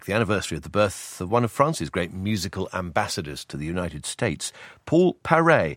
Listen to the word ambassadors in this recording and notice how the first a (unstressed) has the same strong vowel quality as the second a (stressed): /æmˈbæsədəz/:
ambassadors-strong-vowel-in-unstressed-syllable-Petroc-Trelawny-BBC3.mp3